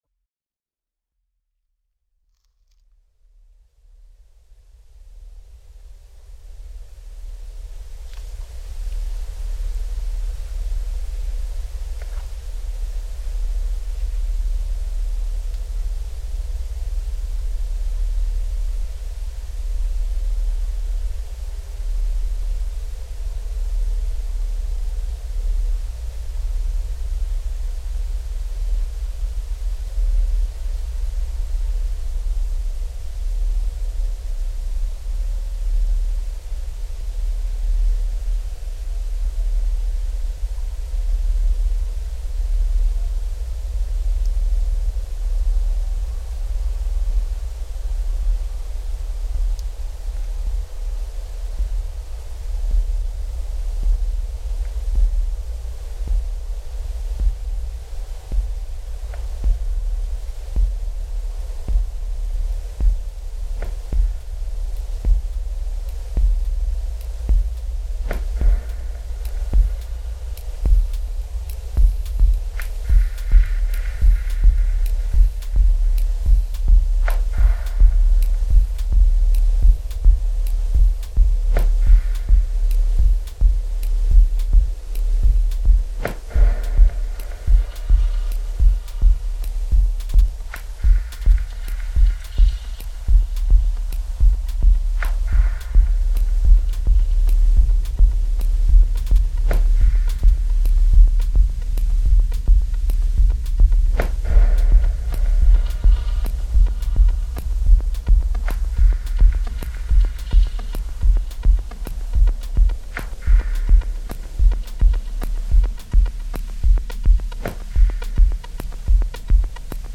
A fresh take on the sounds of Stanley Dock, Liverpool